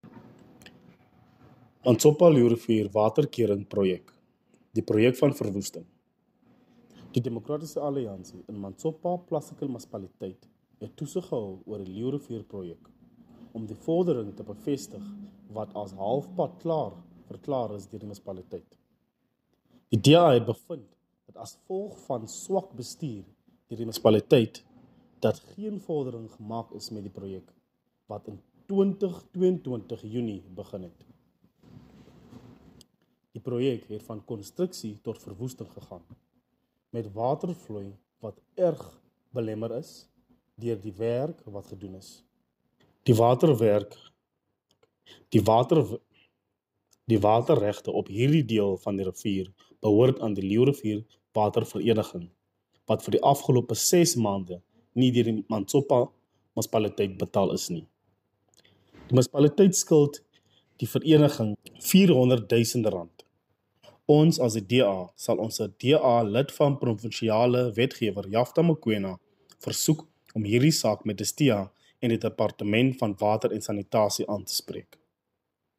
Engelse klankgrepe van Rdl. Lyle Bouwer